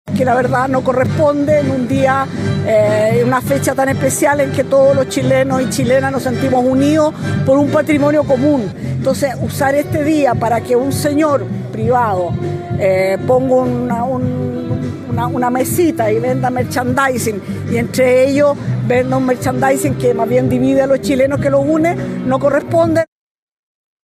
Al respecto, la secretaria de Estado enfatizó en que no corresponde vender artículos que dividen a la ciudadanía en este tipo de actividades.